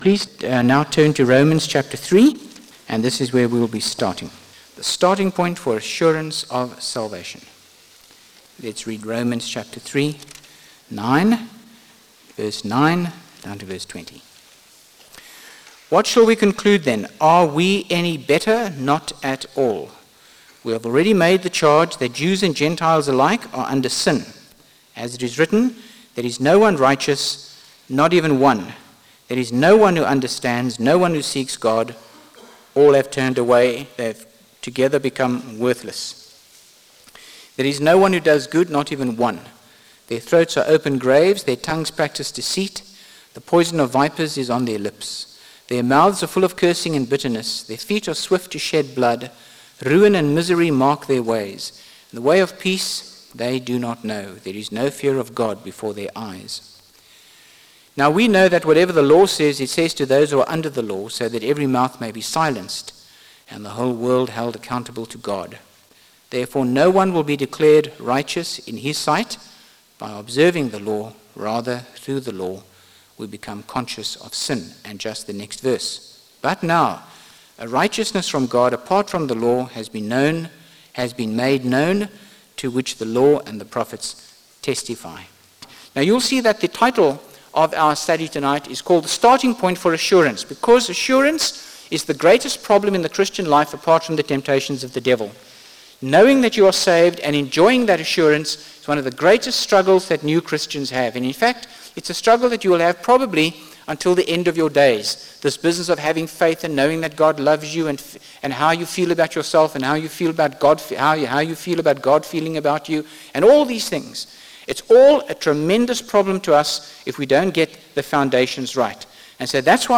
by Frank Retief | Jan 27, 2025 | Frank's Sermons (St James) | 0 comments